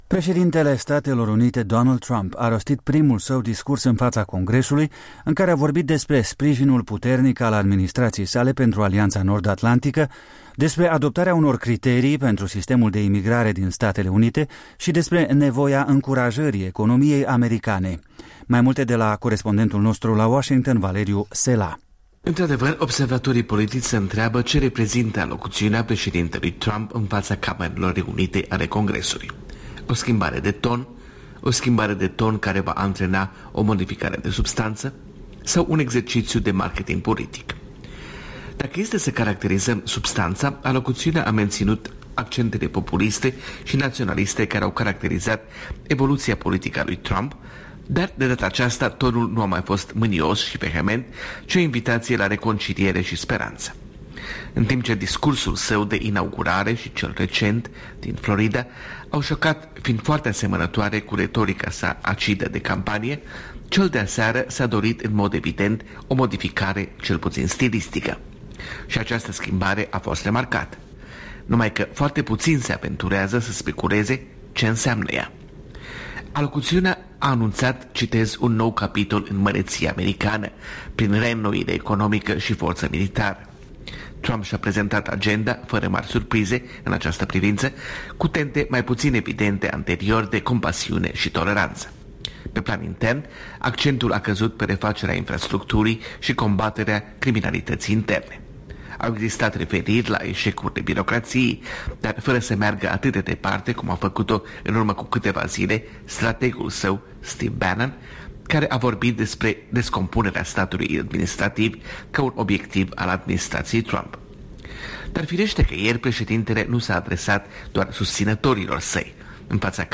Corespondența zilei de la Washington.